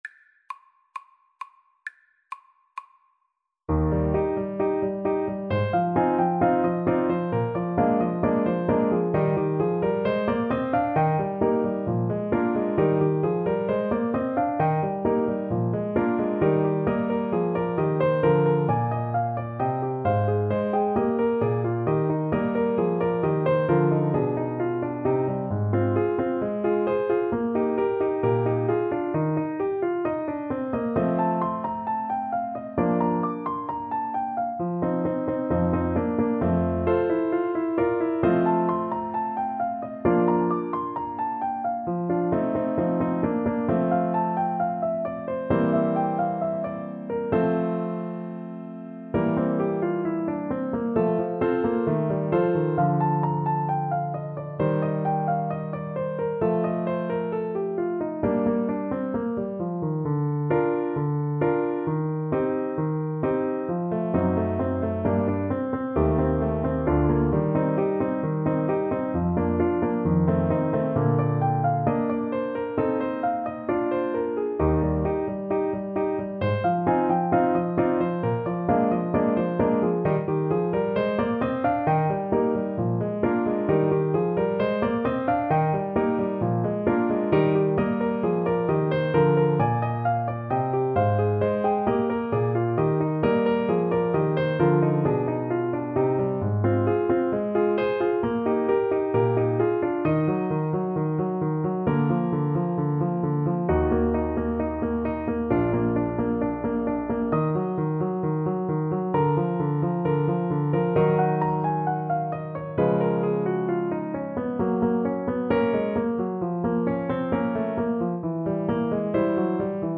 Eb major (Sounding Pitch) F major (Clarinet in Bb) (View more Eb major Music for Clarinet )
=132 Allegro assai (View more music marked Allegro)
2/2 (View more 2/2 Music)
Classical (View more Classical Clarinet Music)